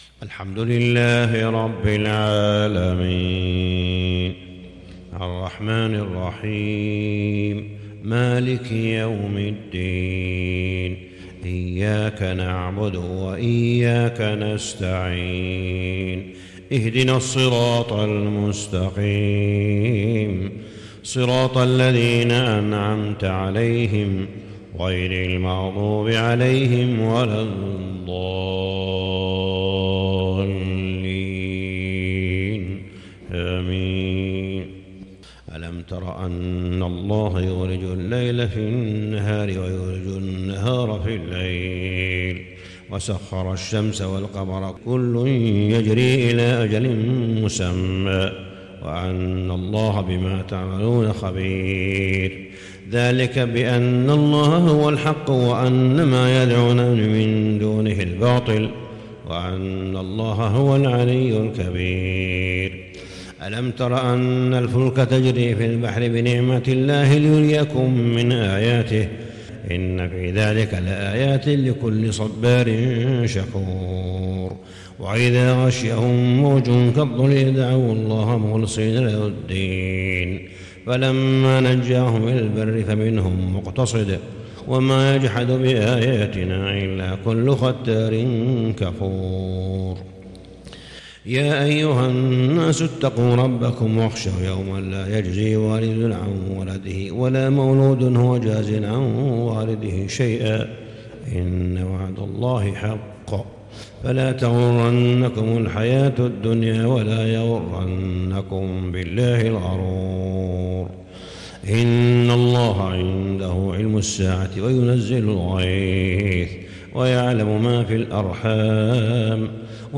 صلاة الفجر للشيخ صالح بن حميد 7 ربيع الأول 1442 هـ
تِلَاوَات الْحَرَمَيْن .